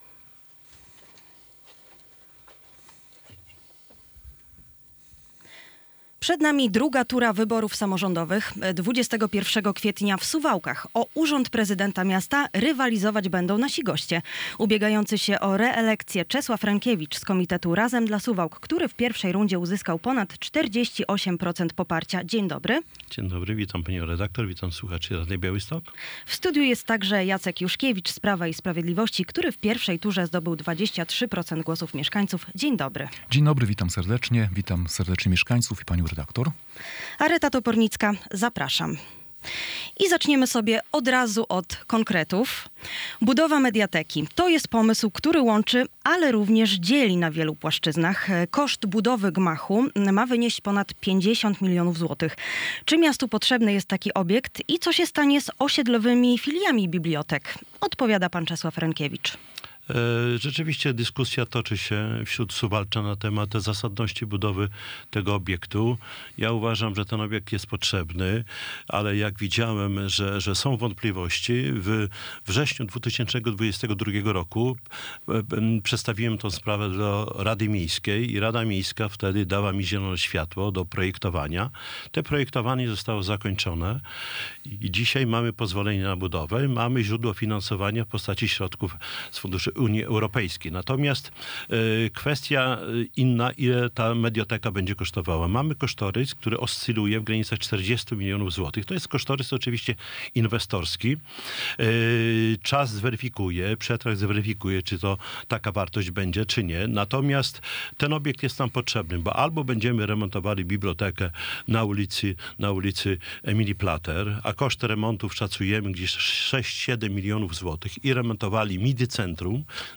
Druga tura wyborów - rozmowa z kandydatami na prezydenta Suwałk [wideo]